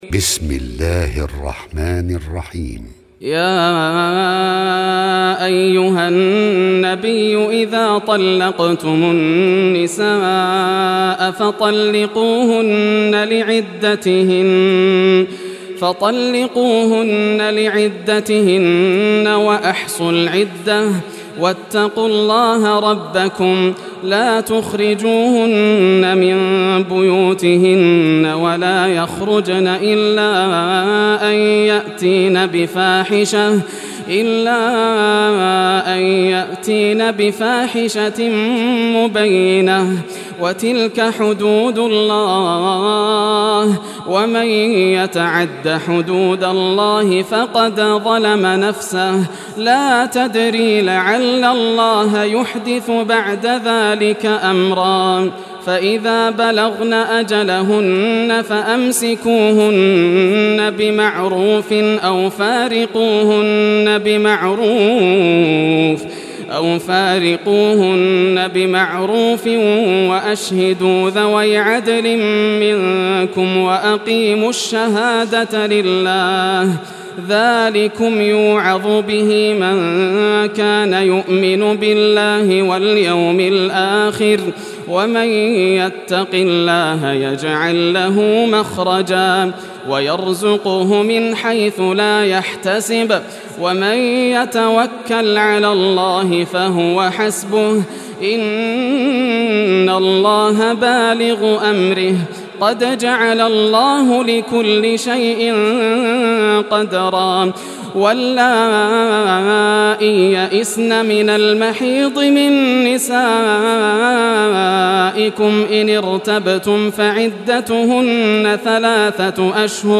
Surah At-Talaq Recitation by Yasser al Dosari
Surah At-Talaq, listen or play online mp3 tilawat / recitation in Arabic in the beautiful voice of Sheikh Yasser al Dosari. Download audio tilawat of Surah At-Talaq free mp3 in best audio quality.